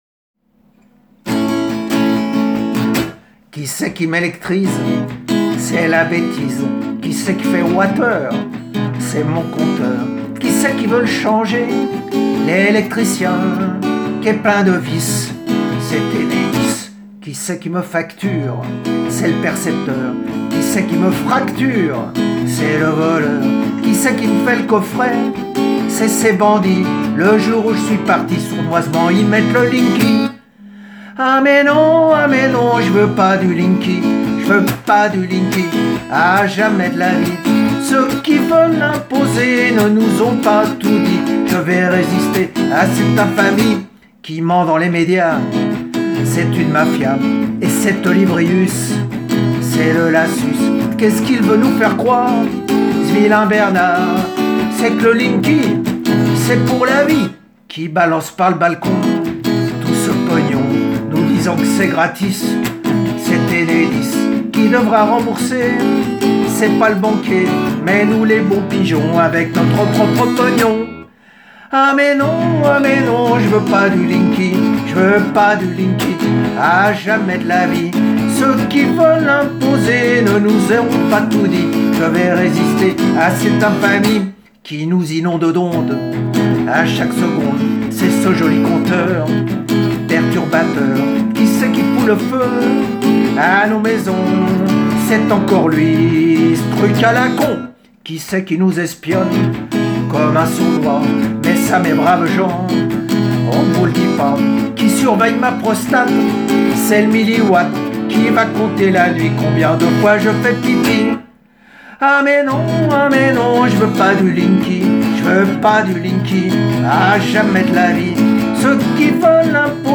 Voilà une chanson, au rythme chic et entraînant, qui a tout pour devenir l’hymne des anti-Linky dans les Pyrénées-Orientales !